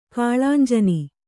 ♪ kāḷānjani